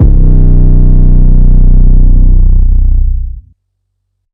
808 - Antidote [ B ].wav